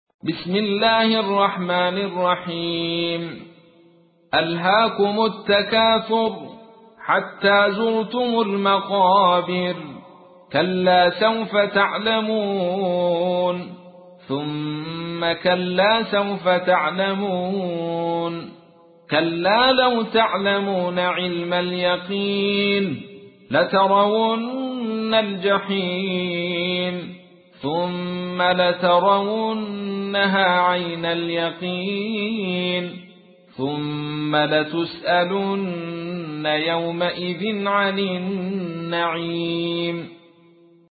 تحميل : 102. سورة التكاثر / القارئ عبد الرشيد صوفي / القرآن الكريم / موقع يا حسين